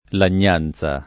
lagnanza [ lan’n’ # n Z a ] s. f.